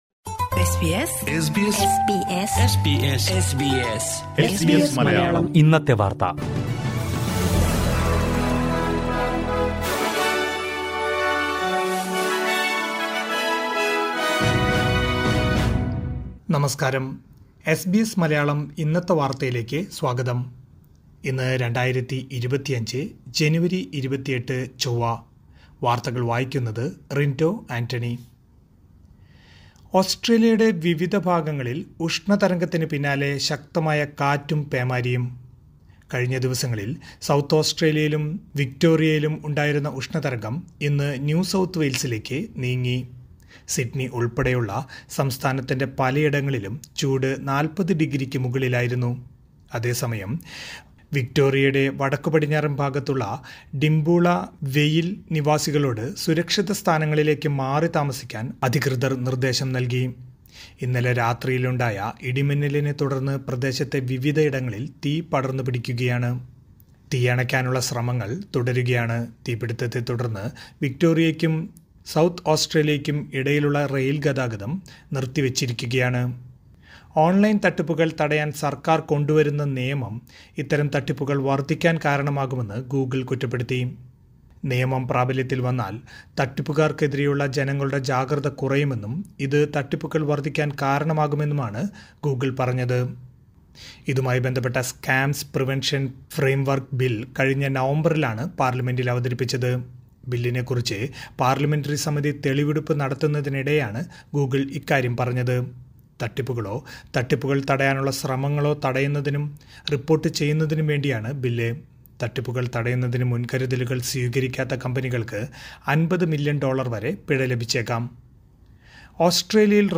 2025 ജനുവരി 28ലെ ഓസ്‌ട്രേലിയയിലെ ഏറ്റവും പ്രധാന വാര്‍ത്തകള്‍ കേള്‍ക്കാം...